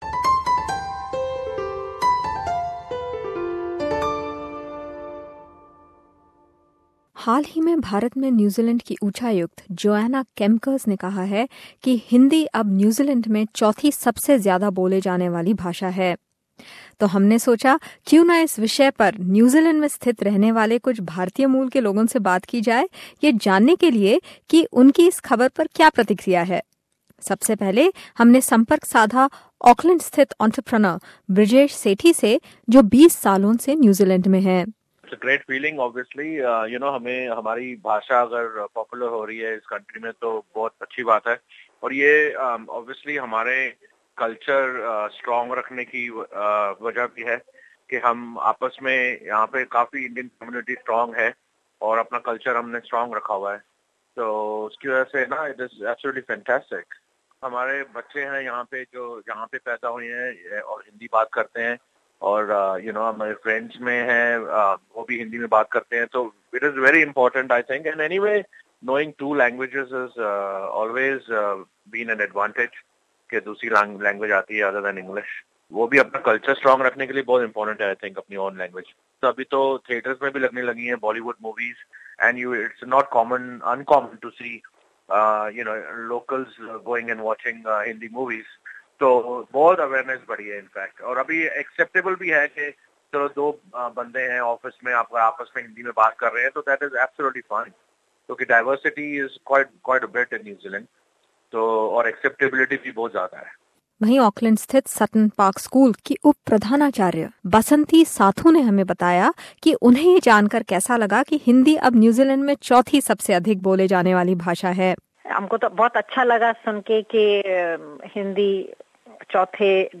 New Zealand's High Commissioner to India Joanna Kempkers recently said that Hindi has become the 4th most spoken langauge in New Zealand. We decided to speak to a few Indian-Kiwis to know how they feel about Hindi being so widely spoken. To know more tune in to this report.